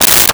Plastic Zipper Short
Plastic Zipper Short.wav